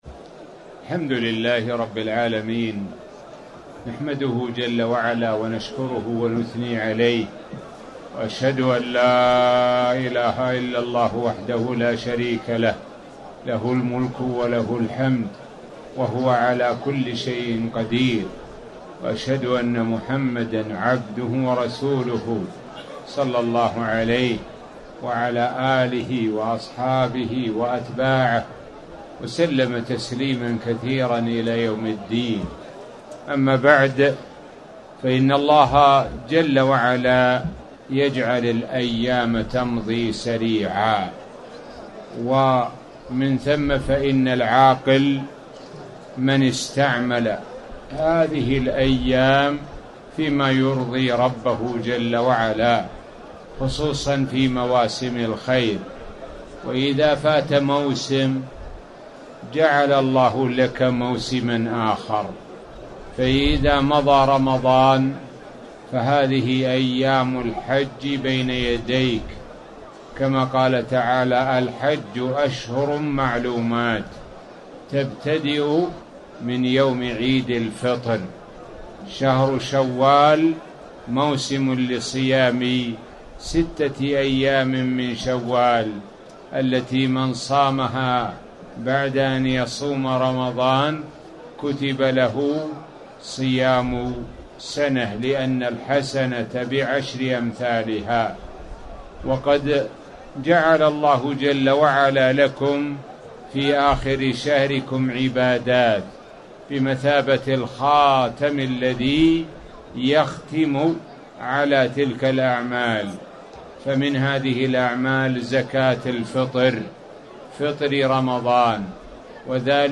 تاريخ النشر ٢٩ رمضان ١٤٣٩ هـ المكان: المسجد الحرام الشيخ: معالي الشيخ د. سعد بن ناصر الشثري معالي الشيخ د. سعد بن ناصر الشثري زكاة الفطر